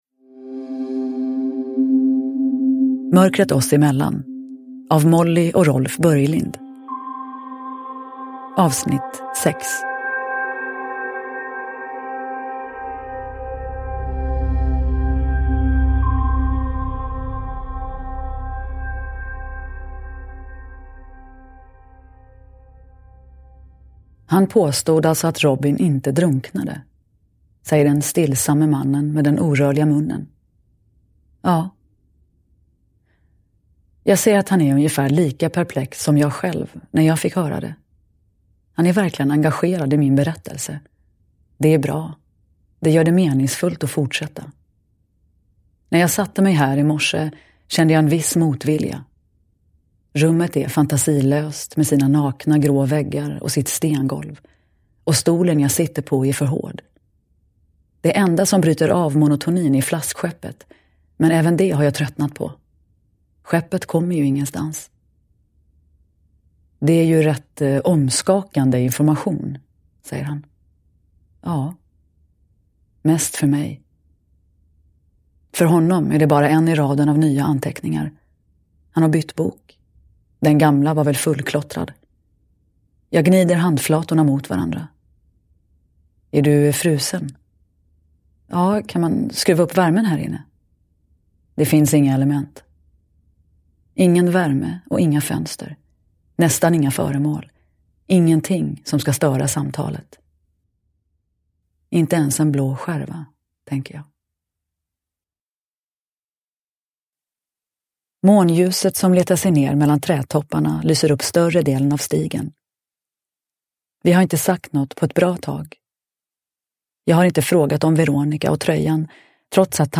Mörkret oss emellan. 6 – Ljudbok – Laddas ner
Uppläsare: Nina Zanjani